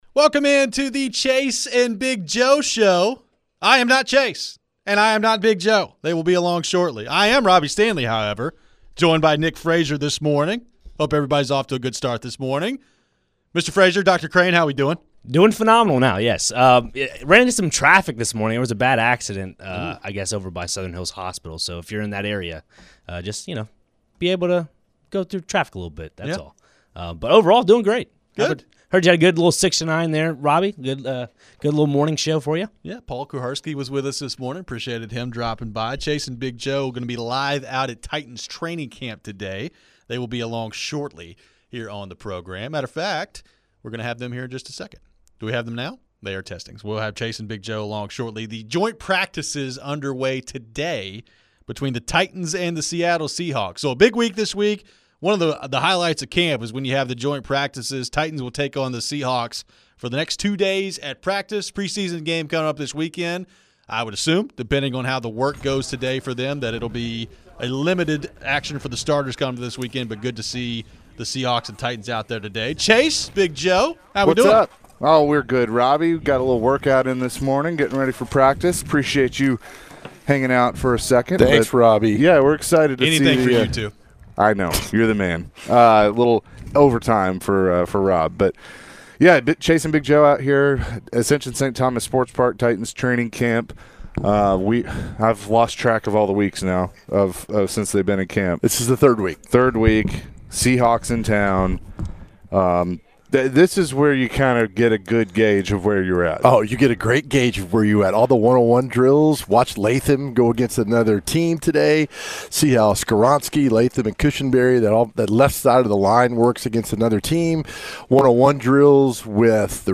the guys were live from St. Thomas Sports Park for Titans training camp.